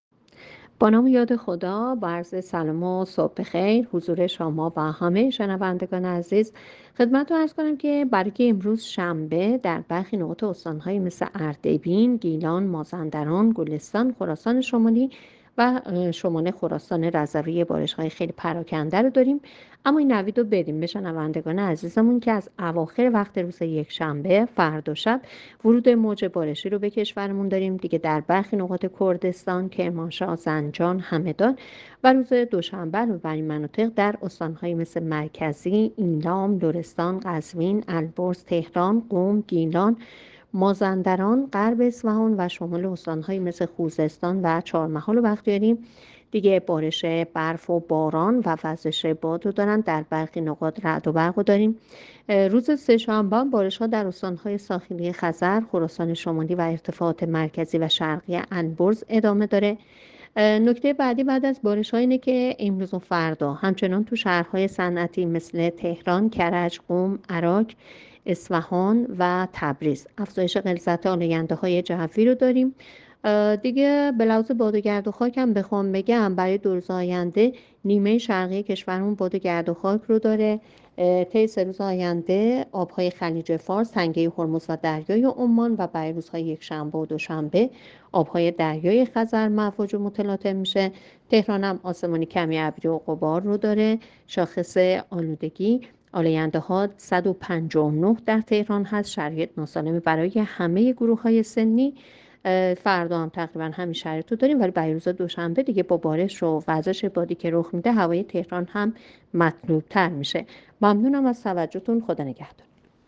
گزارش رادیو اینترنتی پایگاه‌ خبری از آخرین وضعیت آب‌وهوای ۱۷ آذر؛